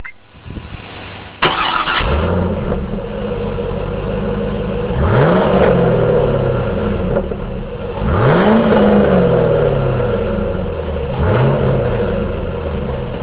Being that the factory Trans Am exhaust is so restrictive and quiet, I choose to have DCV Tech Performance Inc. install the SLP Loudmouth system on my car.
to listen to the factory exhaust and
factory.wav